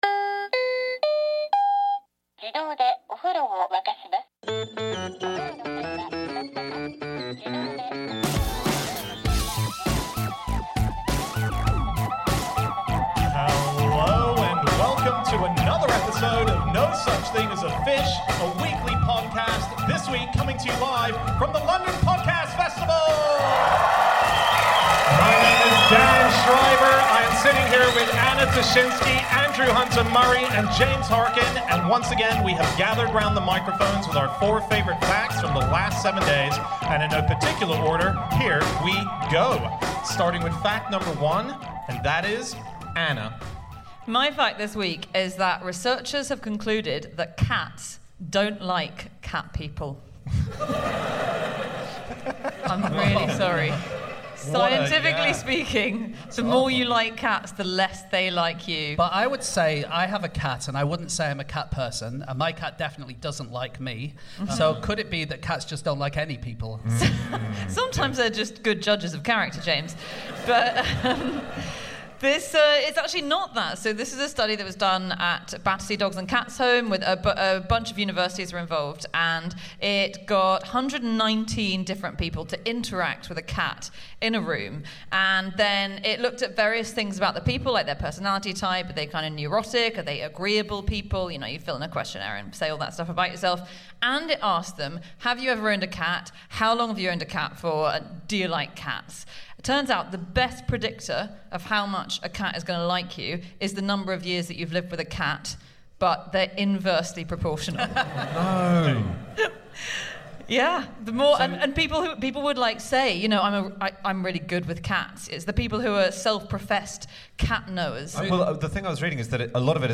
Live from the London Podcast Festival